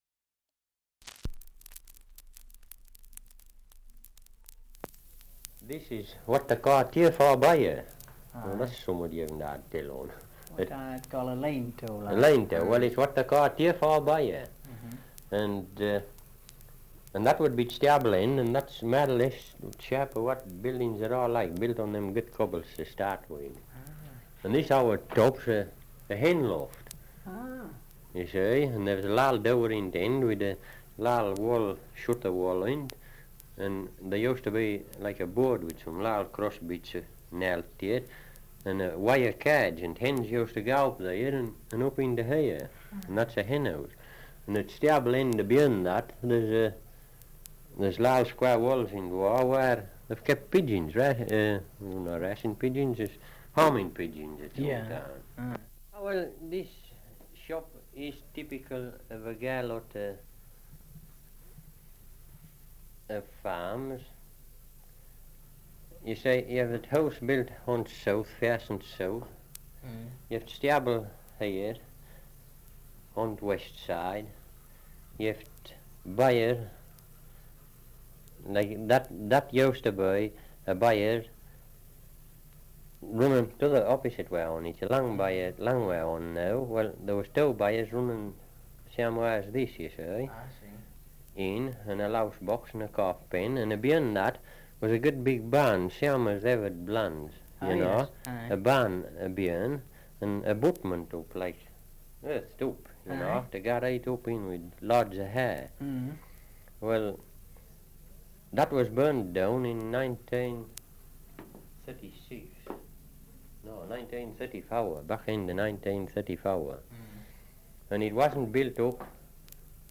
2 - Survey of English Dialects recording in Great Strickland, Westmorland
78 r.p.m., cellulose nitrate on aluminium